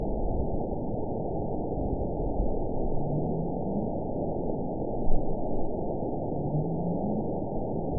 event 910659 date 01/24/22 time 02:28:38 GMT (3 years, 3 months ago) score 9.00 location TSS-AB07 detected by nrw target species NRW annotations +NRW Spectrogram: Frequency (kHz) vs. Time (s) audio not available .wav